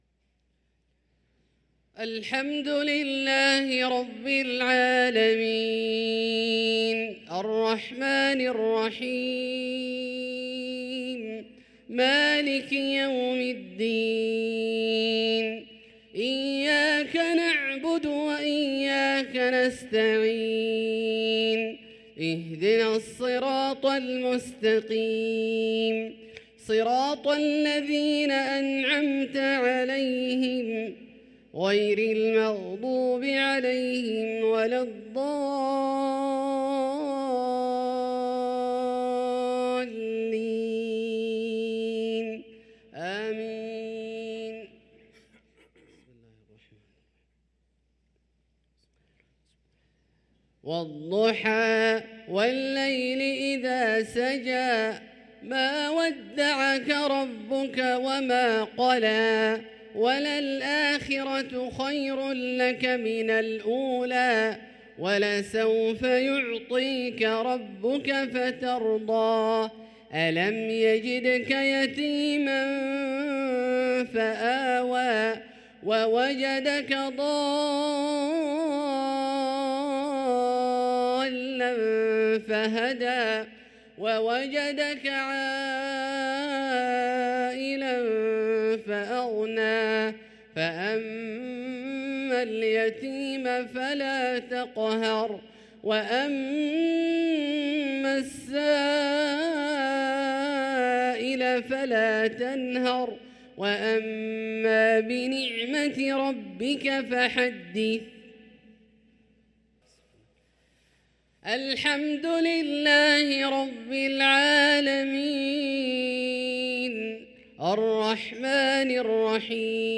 صلاة المغرب للقارئ عبدالله الجهني 13 ربيع الأول 1445 هـ